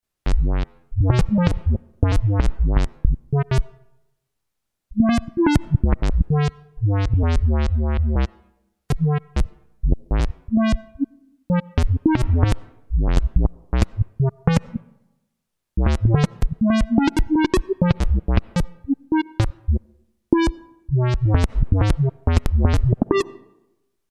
NPC Speech Sounds
Each NPC will have their own unique sounding voice pre-generated using synthesizers.
NOTE: These examples use heavy delay/echo which will not feature on the actual speech sounds.